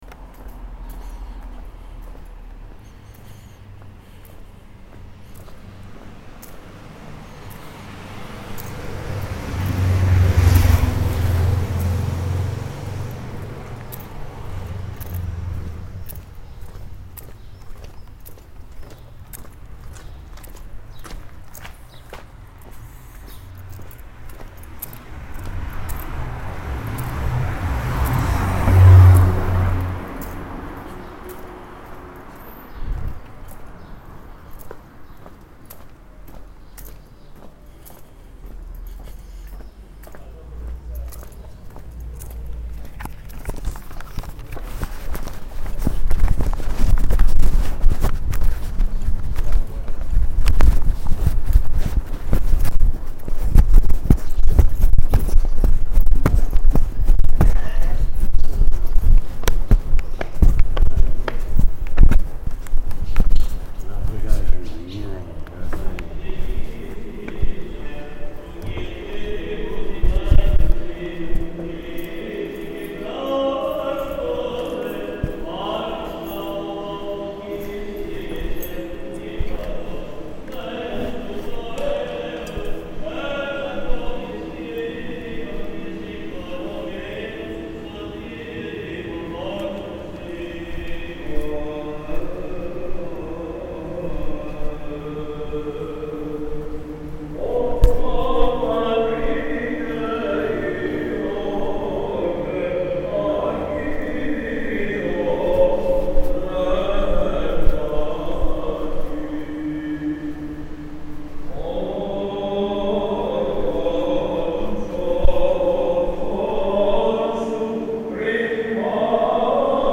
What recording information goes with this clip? Sunday morning, 8:15am, Monastiraki, Athens, Greece What you are hearing is my walk to St. Irene Church in Monastiraki, starting from about two blocks away, and my subsequent entry into the church towards the end of Orthros.